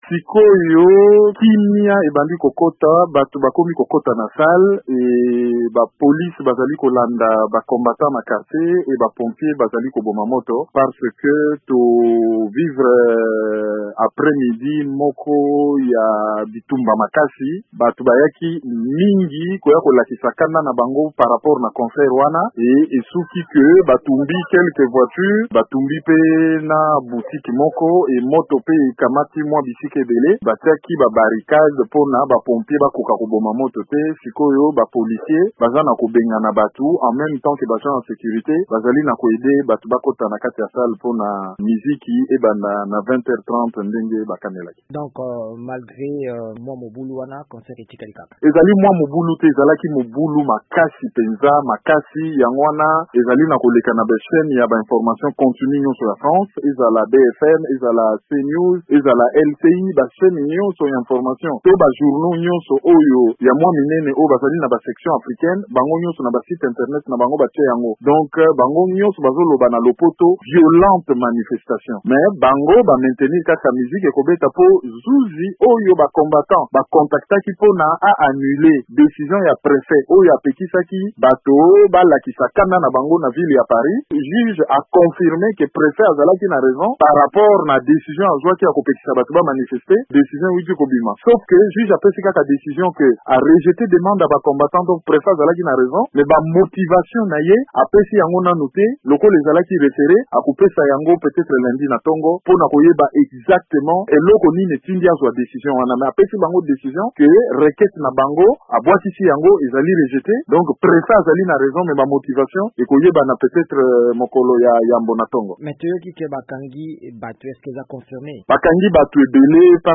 ayanoli na mituna ya VOA Lingala.